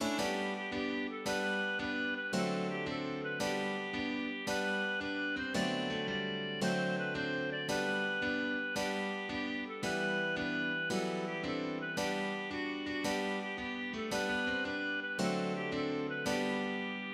Canção Infantil